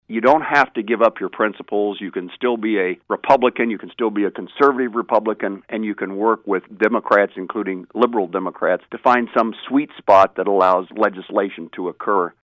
On Thursday, Sen. Jerry Moran spoke with KMAN about the life and legacy of the Russell native, who died Sunday at the age of 98.